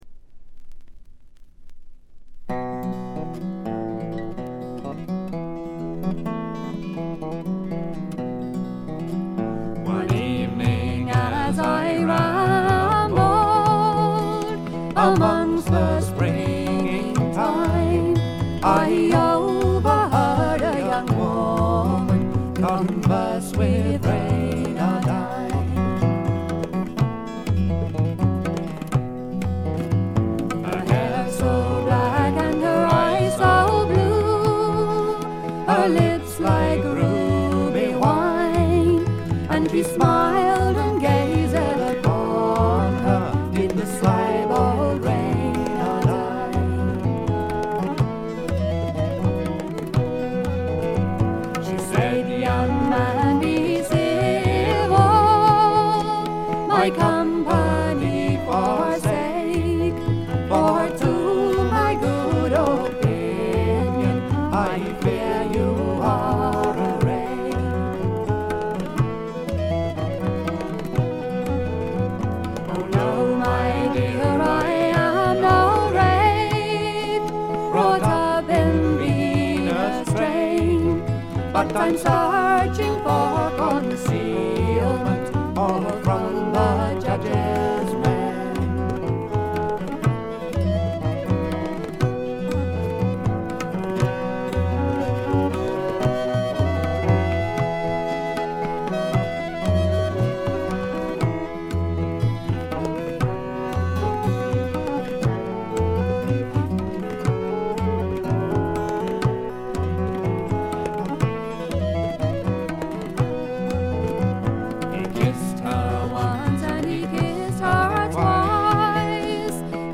バックグラウンドノイズや軽微なチリプチは普通レベルで出ますが特筆するようなノイズはありません。
また専任のタブラ奏者がいるのも驚きで、全編に鳴り響くタブラの音色が得も言われぬ独特の味わいを醸しだしています。
試聴曲は現品からの取り込み音源です。